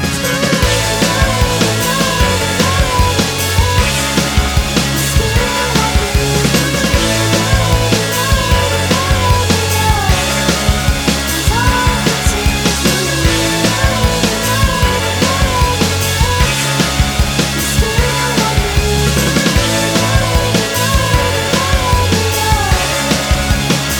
Minus Main Guitars Indie / Alternative 2:45 Buy £1.50